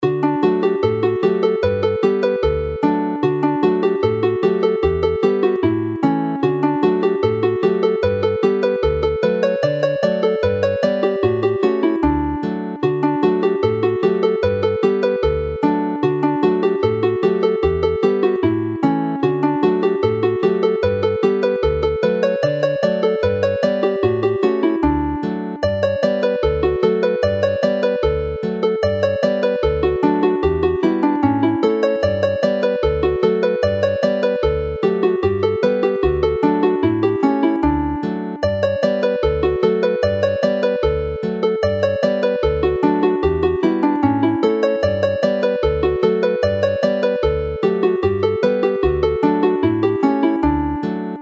The reel Y Farchnad (the market) ends the set in a light and jolly way